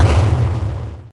Techmino/media/effect/chiptune/clear_3.ogg at beff0c9d991e89c7ce3d02b5f99a879a052d4d3e
clear_3.ogg